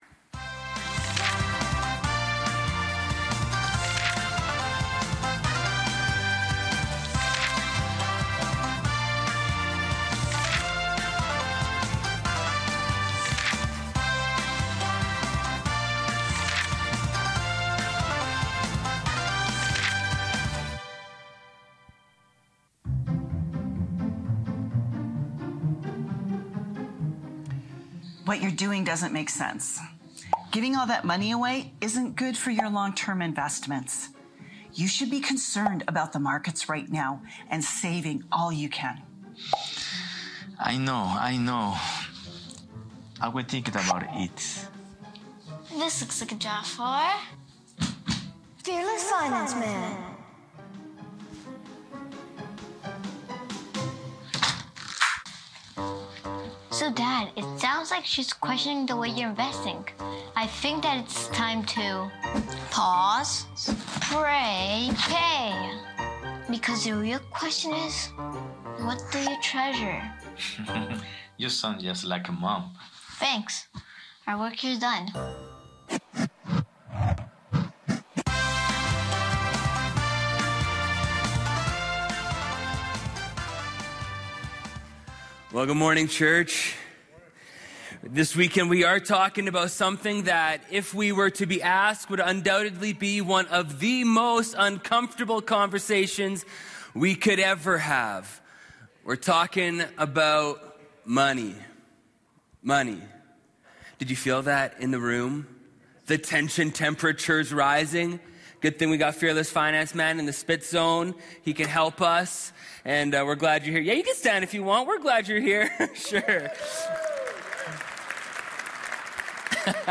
English Teaching MP3 This Weekend's Scriptures...